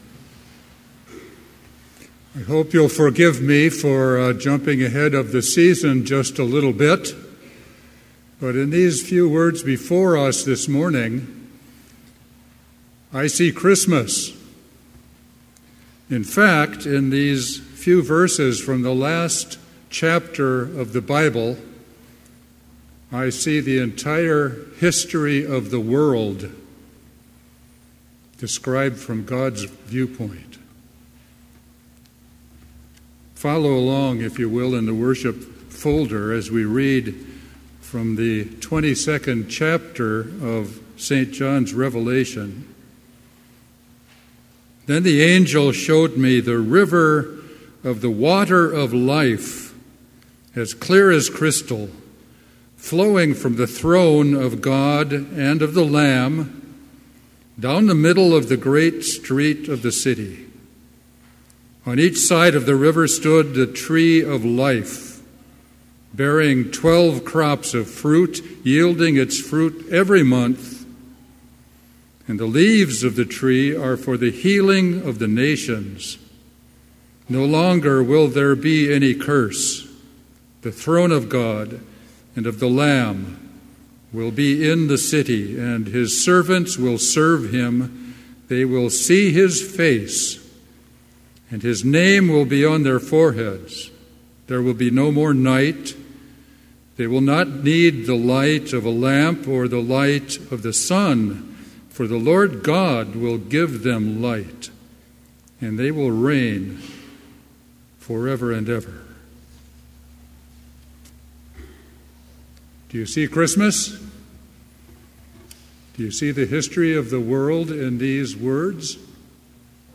Complete service audio for Chapel - November 28, 2017
Order of Service Prelude Hymn 542, vv. 1 & 2, In Heaven Above Reading: Revelation 22:1-5 Devotion The Lord's Prayer Hymn 542, vv. 3 & 4, In heav'n above… Blessing Postlude